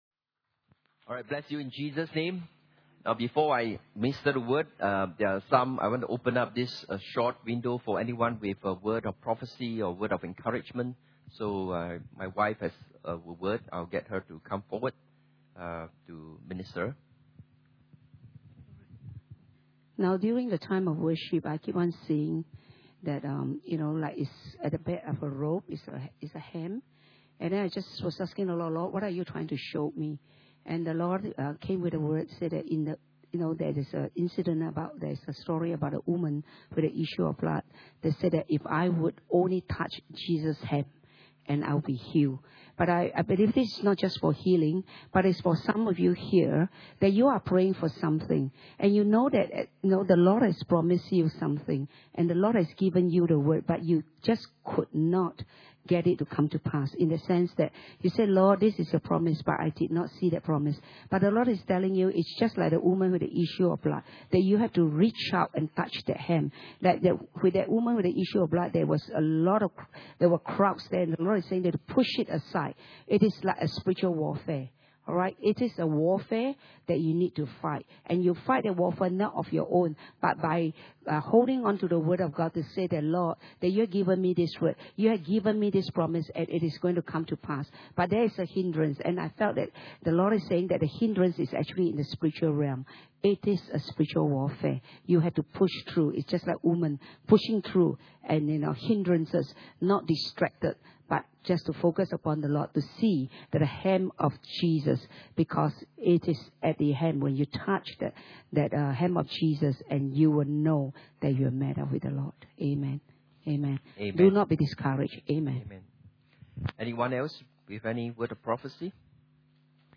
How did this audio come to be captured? Inhouse Service Type: Sunday Morning « The Cross the place of divine exchange Holiness and Fear of God pt 2